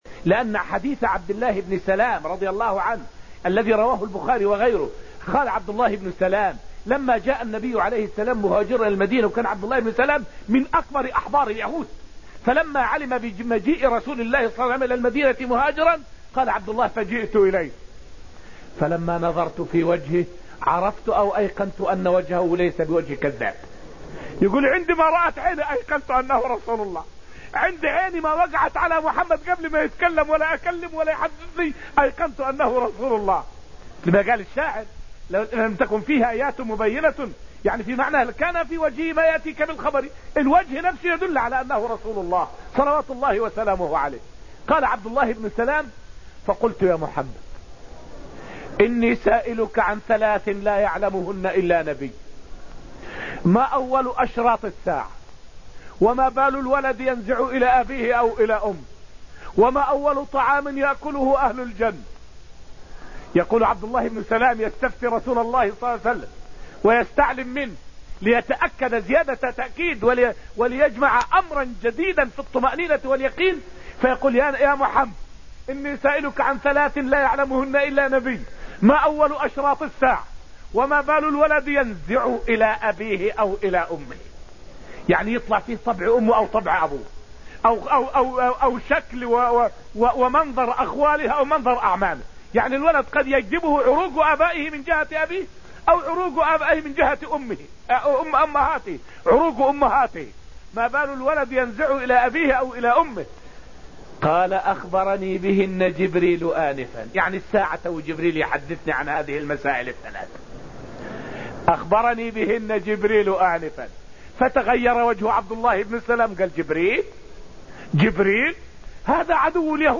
فائدة من الدرس الخامس من دروس تفسير سورة الواقعة والتي ألقيت في المسجد النبوي الشريف حول أسئلة عبد الله بن سلام للنبي لاختبار نبوته.